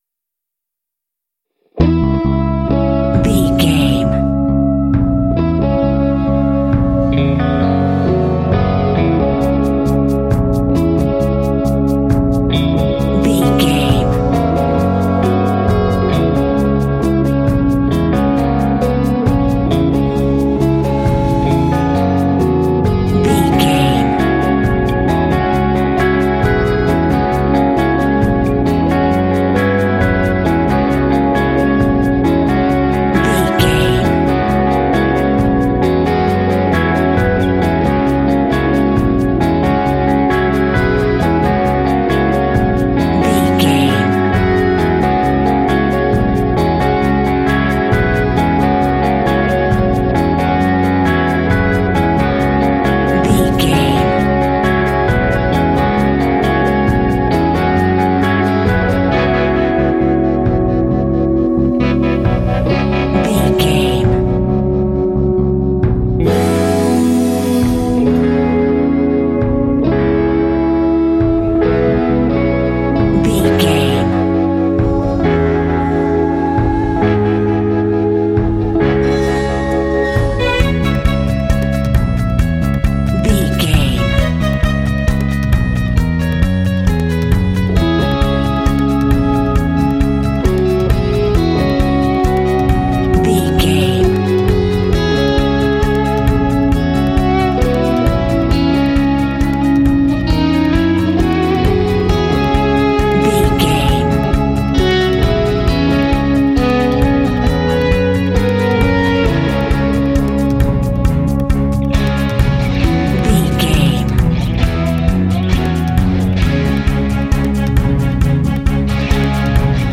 Epic / Action
Aeolian/Minor
C#
dramatic
foreboding
tension
electric guitar
synthesiser
drums
percussion
bass guitar
strings
cinematic
orchestral
film score